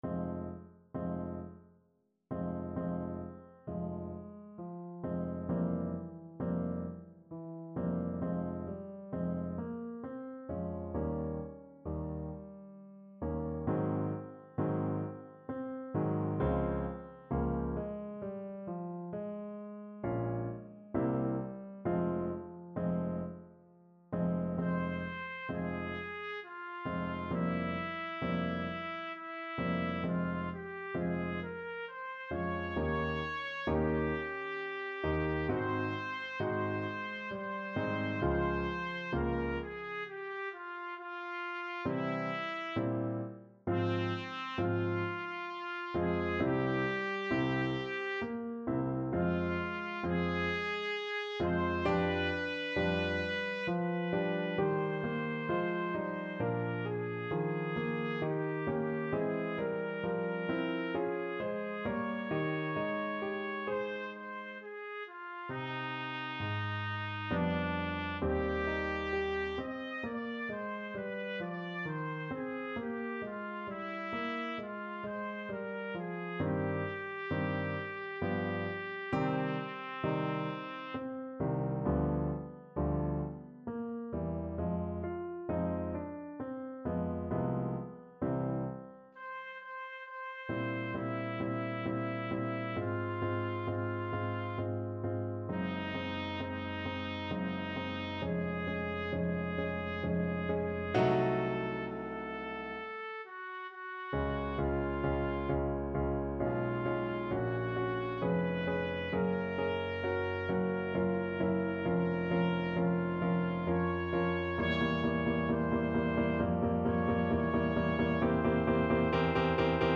Trumpet version